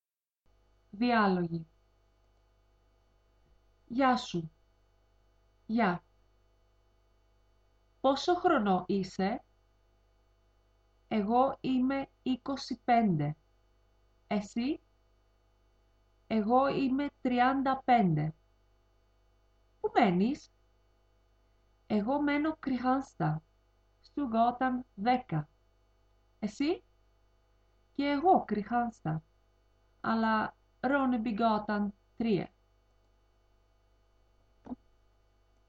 Διάλογοι
dialog2.mp3